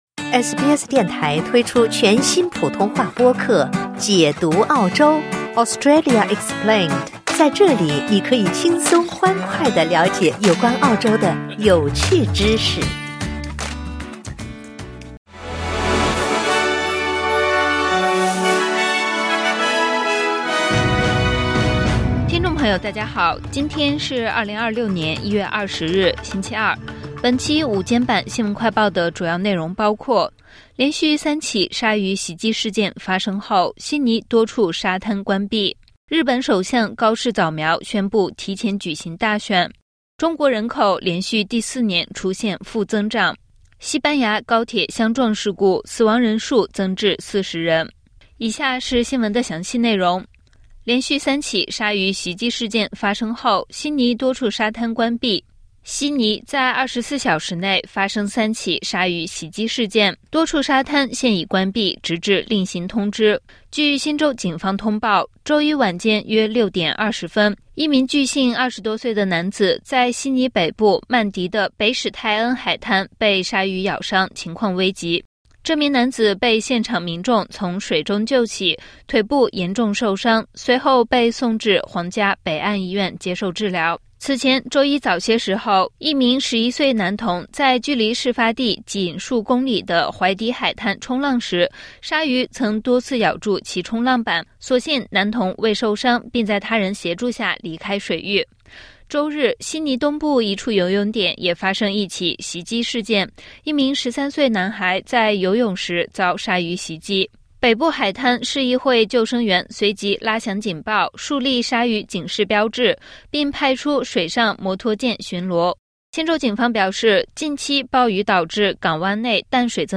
【SBS新闻快报】24小时内三起鲨鱼袭击 悉尼多处沙滩关闭